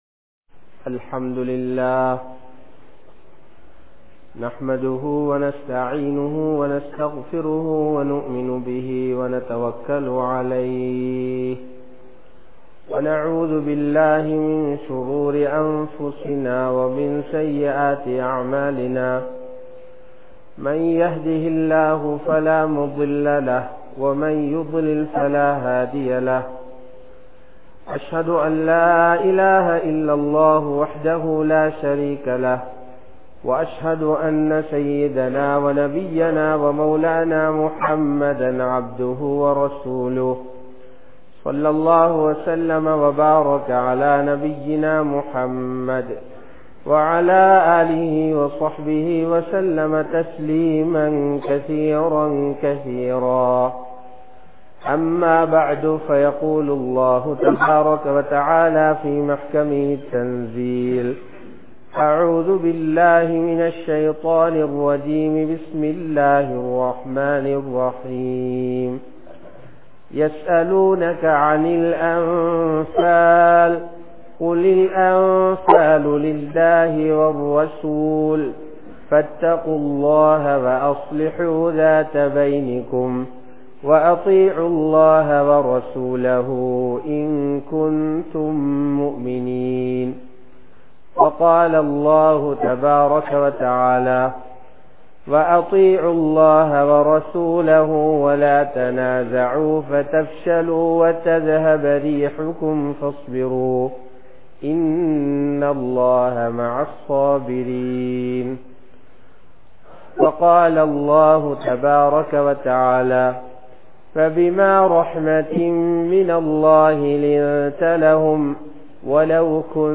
Ottrumai (ஒற்றுமை) | Audio Bayans | All Ceylon Muslim Youth Community | Addalaichenai
Silmiyapura, Noor Jumua Masjidh